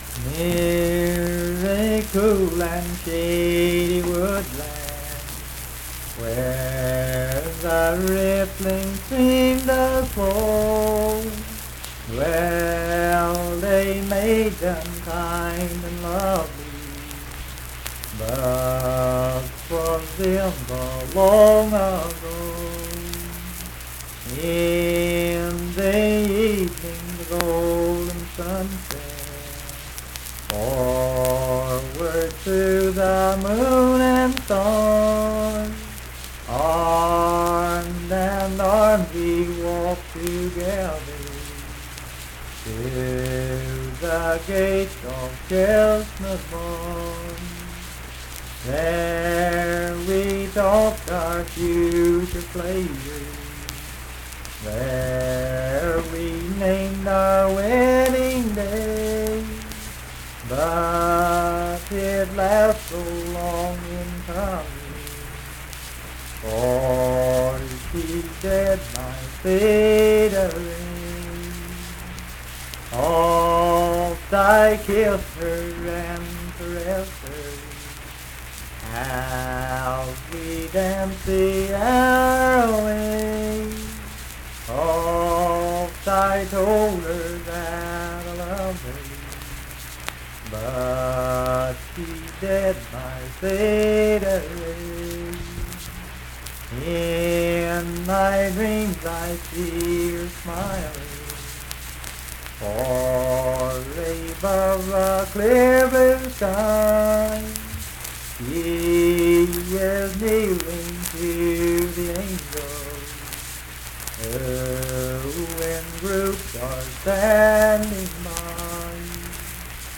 Unaccompanied vocal music performance
Verse-refrain 6(4).
Voice (sung)